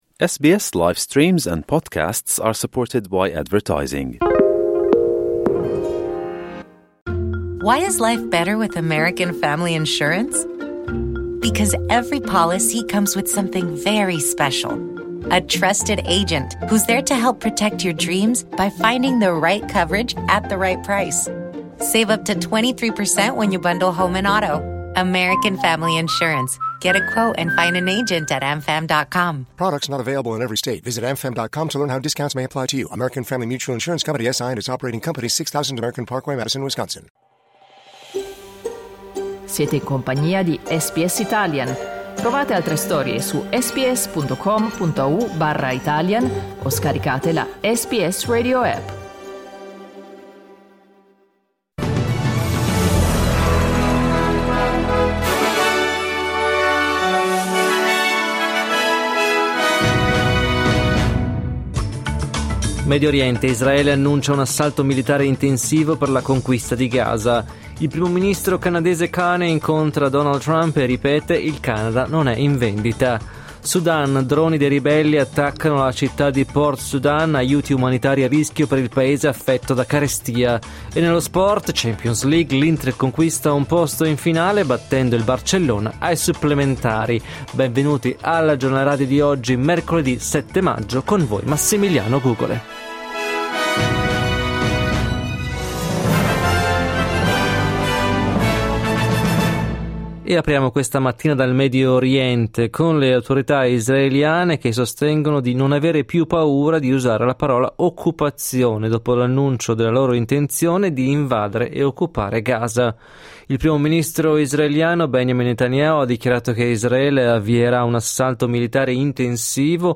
Giornale radio mercoledì 7 maggio 2025
Il notiziario di SBS in italiano.